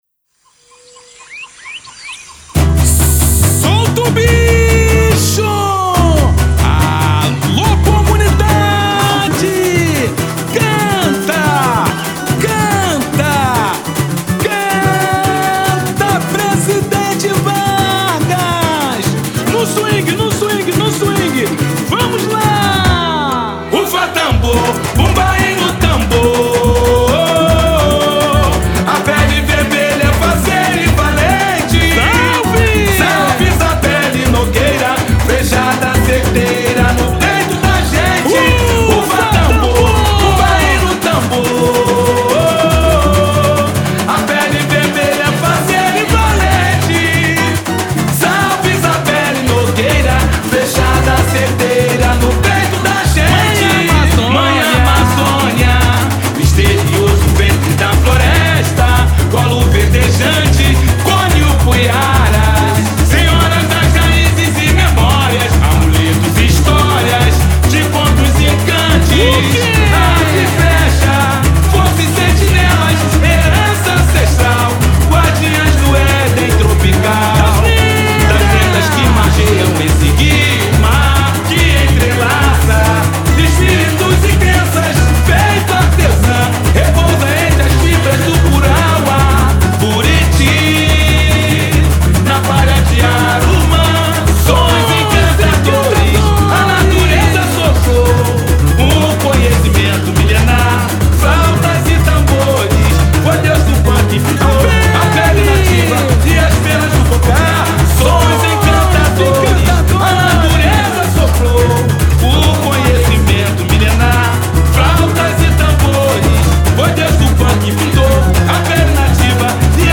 samba
Obra será apresentada na disputa oficial da escola no próximo dia 10 de outubro, no Almirante Hall